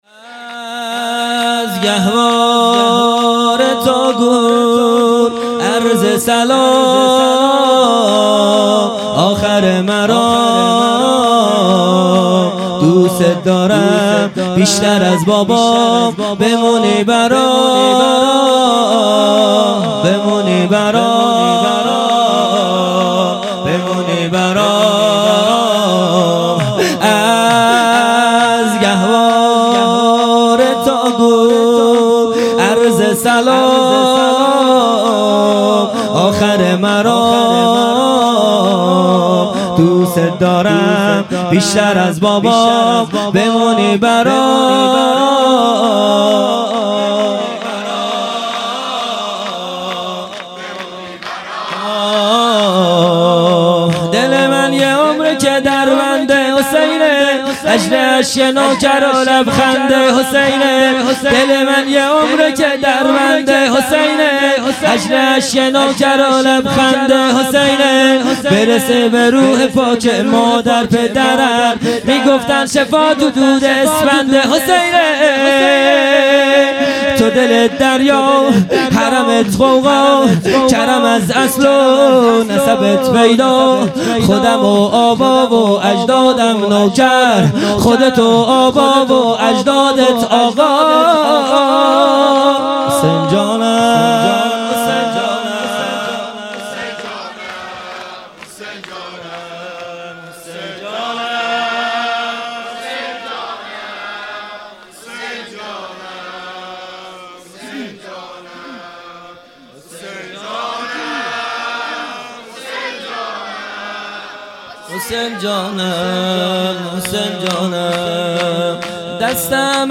شور ۳ | از گهواره تا گور مداح
فاطمیه دوم ۱۴۰۱_شب پنجم